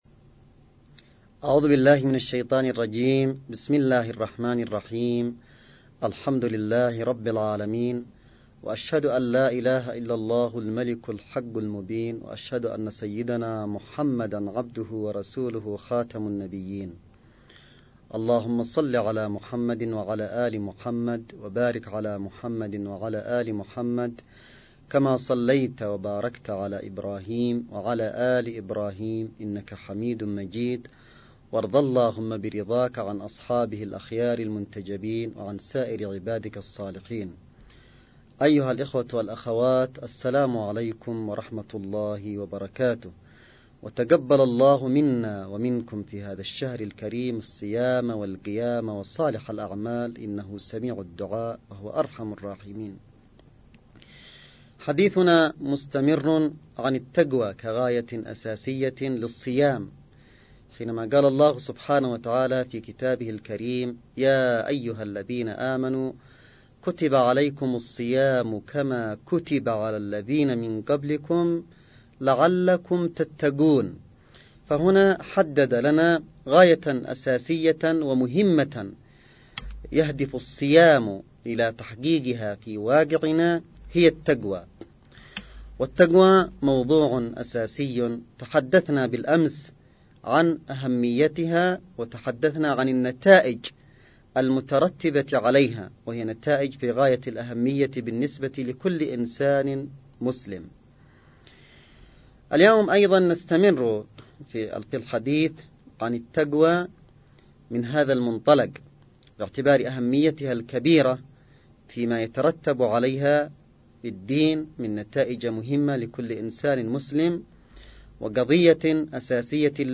نص + أستماع : الجزء الثاني لمحاضرة ( لعلكم تتقون ) للسيد عبد الملك بدر الدين الحوثي
محاضرة_السيد_عبدالملك_بدر_الدين1.mp3